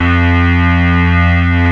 Index of /90_sSampleCDs/Roland LCDP02 Guitar and Bass/BS _Synth Bass 1/BS _Wave Bass
BS  FUZZY 07.wav